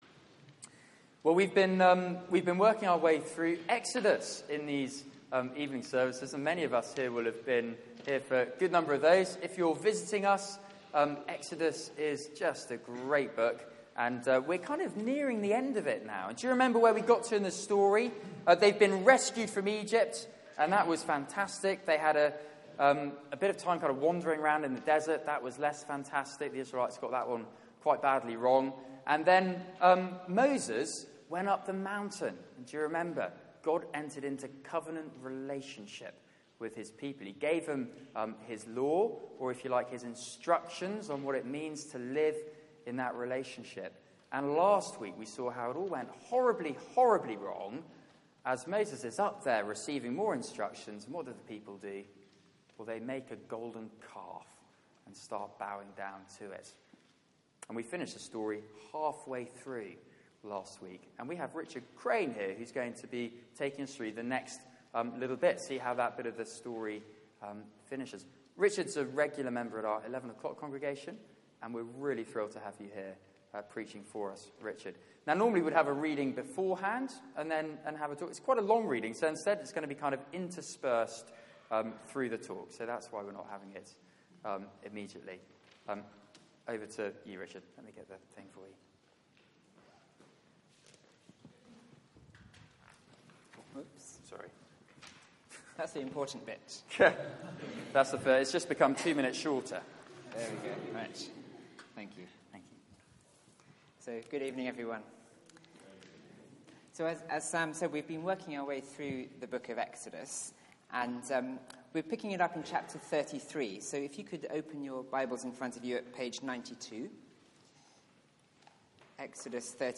Media for 6:30pm Service on Sun 26th Jun 2016 18:30 Speaker
Series: Behold your God Theme: When God passes by Sermon